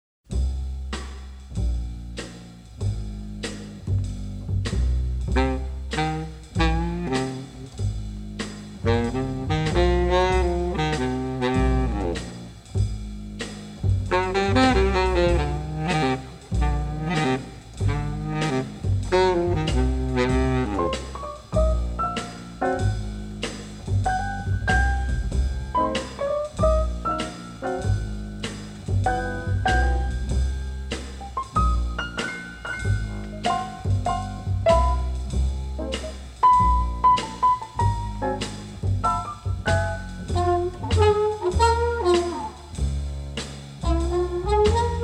jazz and mood cues
shimmering with color, charm and melody